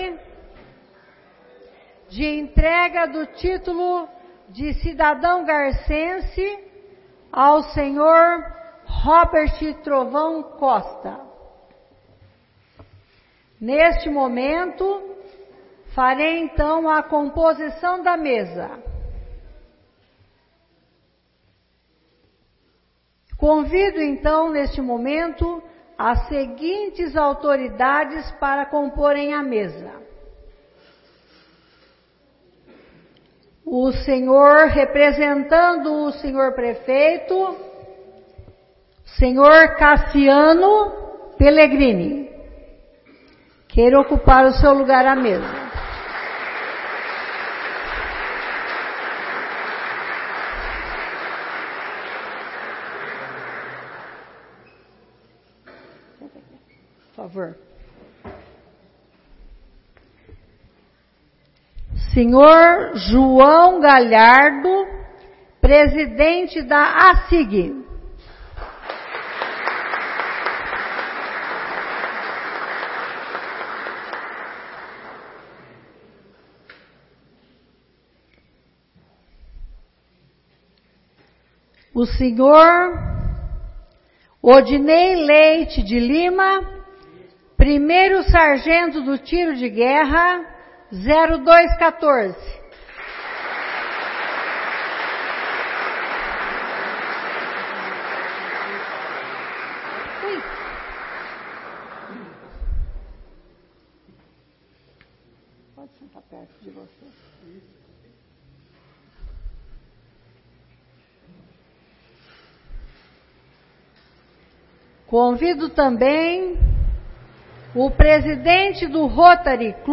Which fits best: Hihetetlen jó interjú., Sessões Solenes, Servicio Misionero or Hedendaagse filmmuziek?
Sessões Solenes